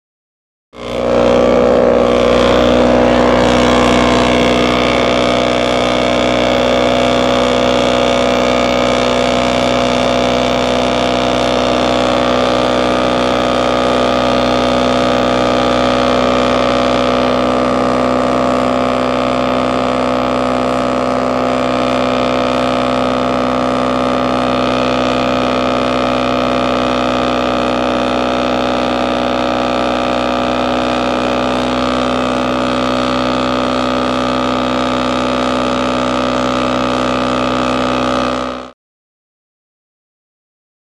Mini Hovercraft; Run ( Ext. ); Mini Hovercraft. Two Stroke Engine. Constant.